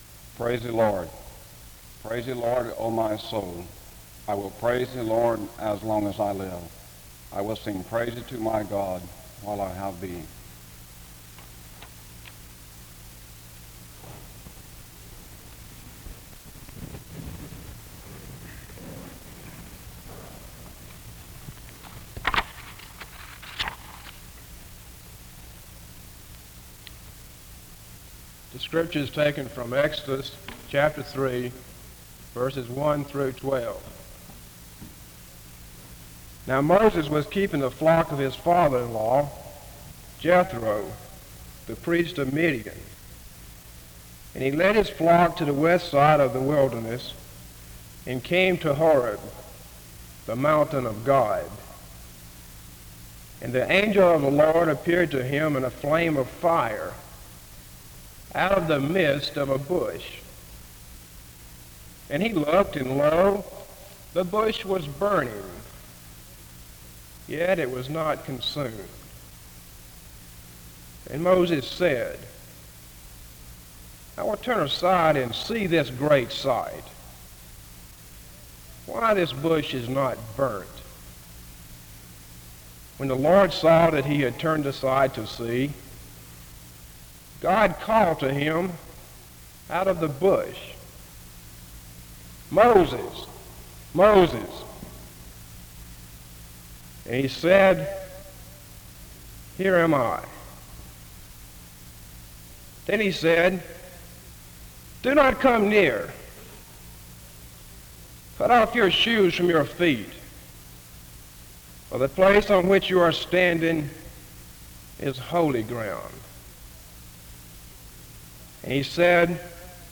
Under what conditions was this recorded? SEBTS Chapel A closing prayer is offered from 15:25-15:49. This service was organized by the Student Coordinating Council.